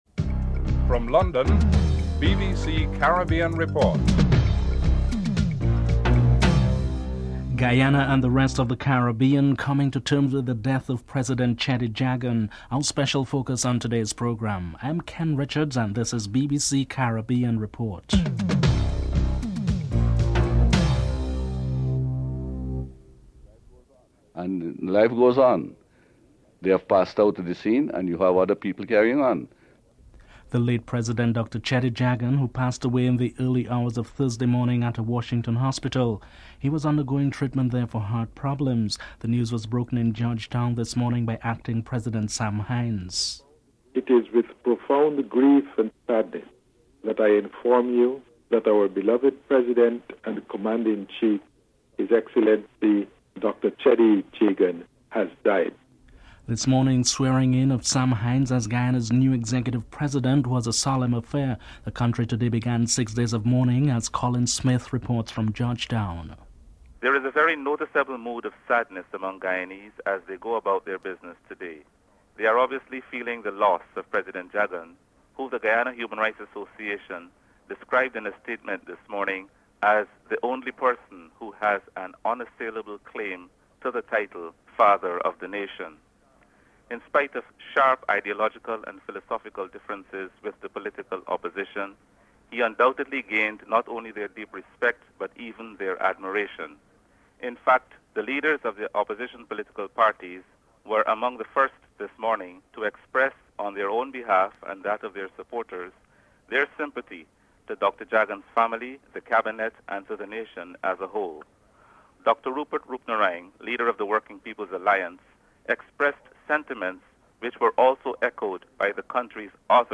1. Headlines (00:00-00:21)
3. In this 1963 interview obtained from the BBC World Service Archives, Dr Cheddi Jagan talks about his early life.